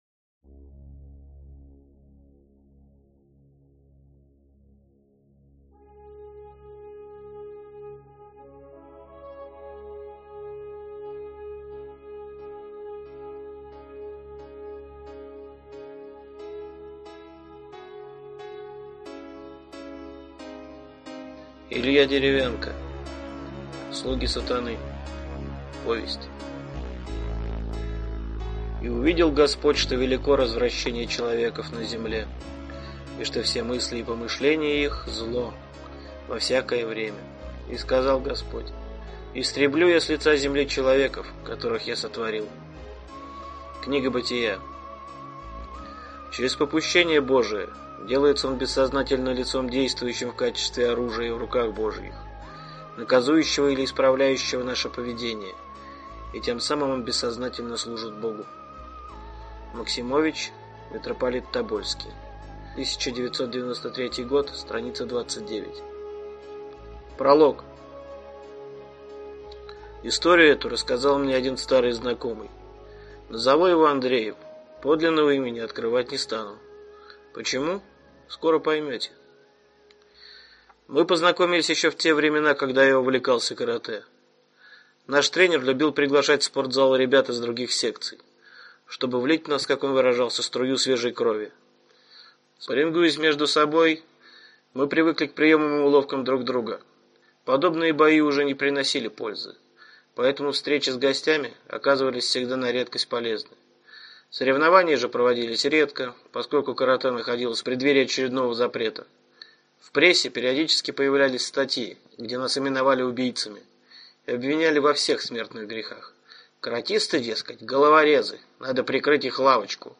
Аудиокнига Слуги сатаны | Библиотека аудиокниг